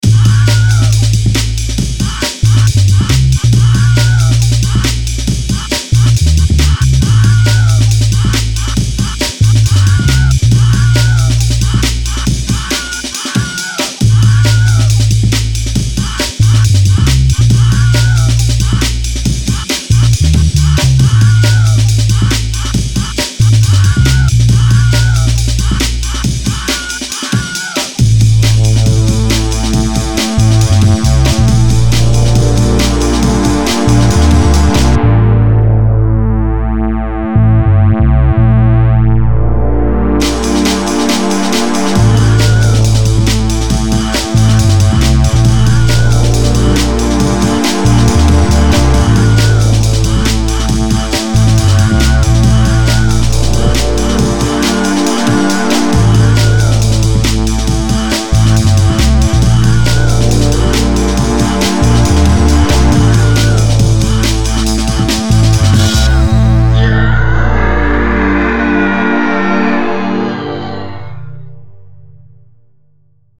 A friend of mine said they were going to make a song incorporating the Amen Break and the Wilhelm scream, but I thought they were just joking.
Filed under: Instrumental | Comments (1)